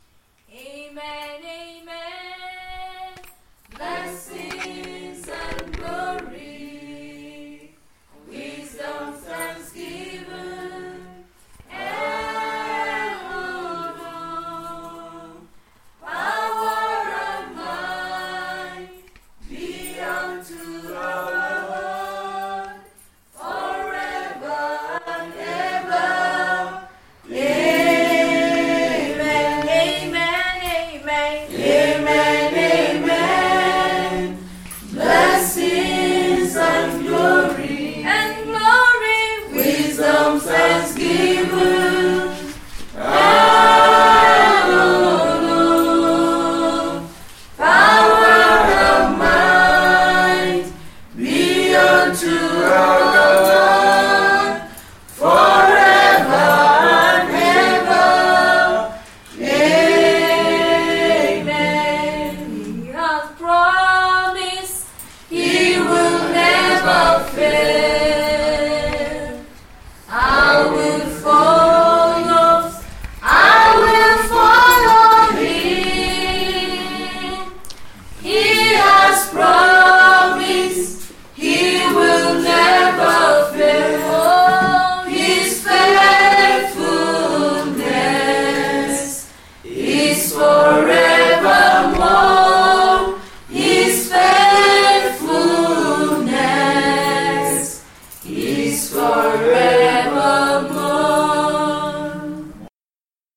Here are five songs performed by the staff members of the ECWA Information and Computer Sciences Institute.  Some were recorded during their morning devotions, while others were part of the school’s 20th anniversary celebrations.
This is a very popular hymn in Nigeria.
wednesday-devotions-first-song-amen-amen.mp3